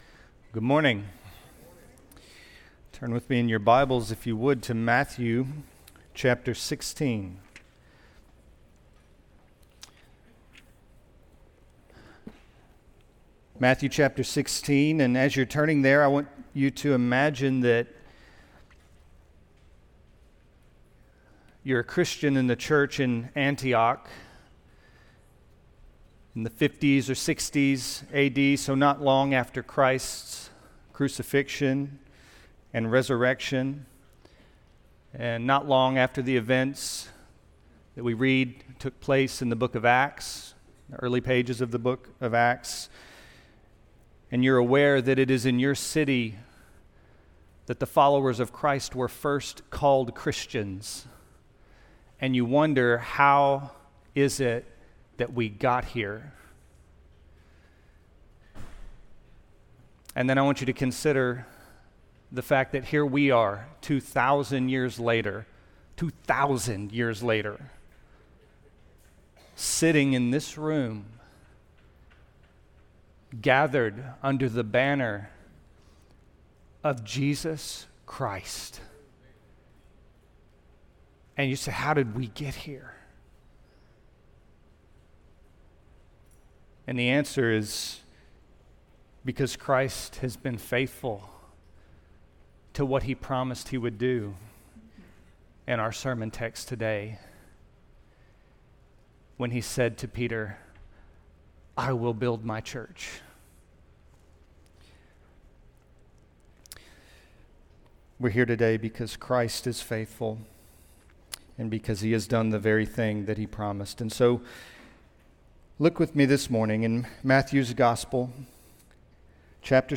Sermons – Grace Church Memphis podcast
The preaching ministry of Grace Church Memphis, located in Memphis, TN.